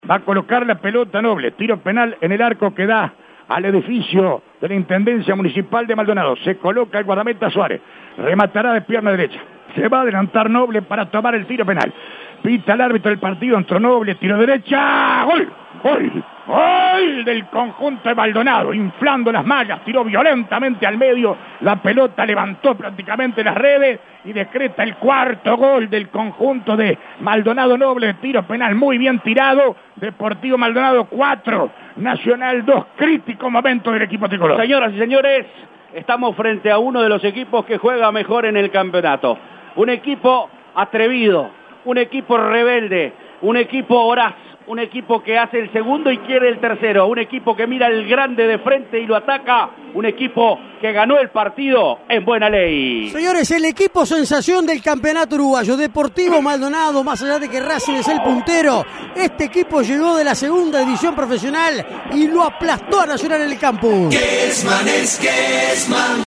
GOLES RELATADOS POR ALBERTO KESMAN